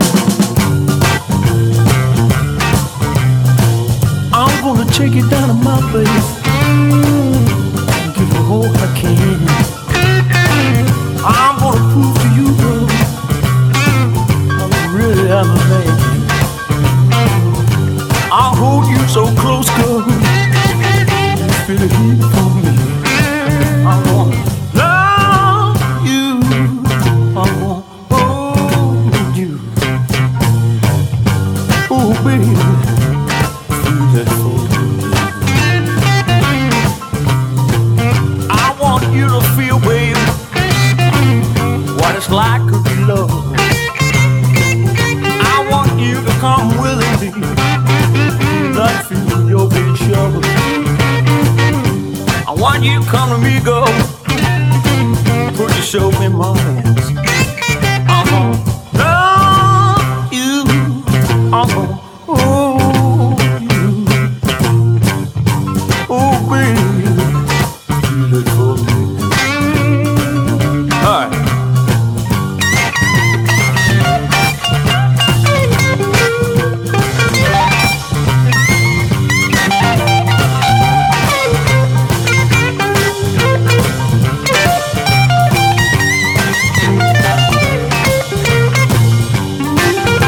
ROCK / 60'S
メキシカン・ティーン・ガレージ・ロックンロール！